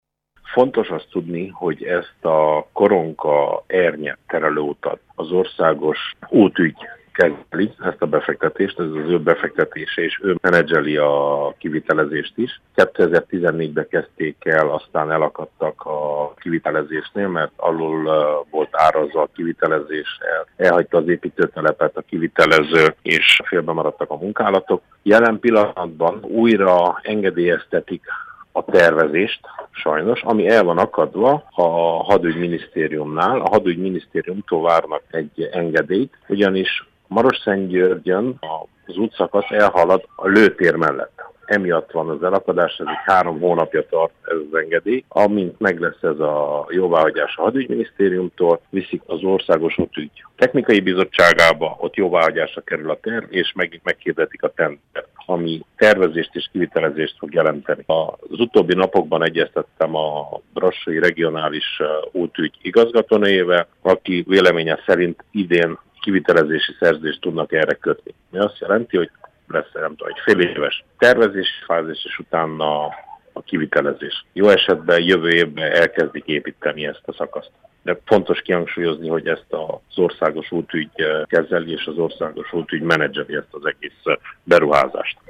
Novák Levente szenátor nyilatkozott rádiónknak.